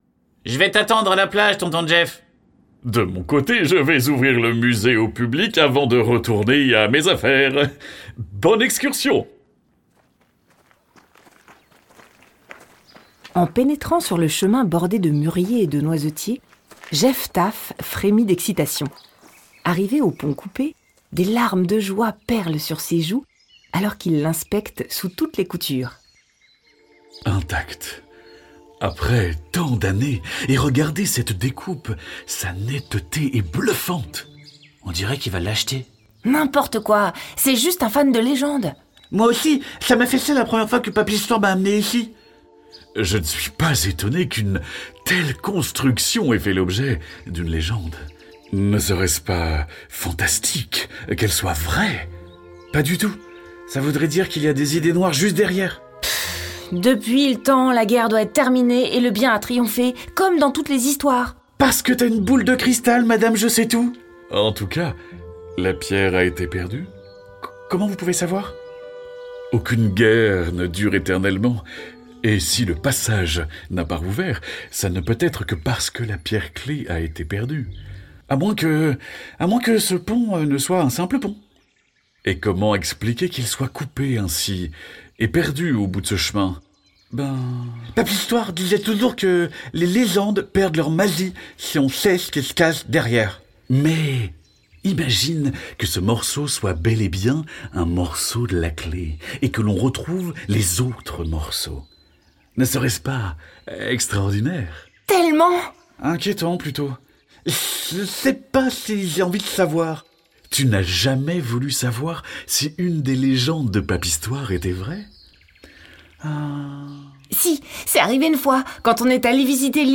Portée par les voix du podcast jeunesse Les P'tites Histoires au plus de 20 millions d'écoutes.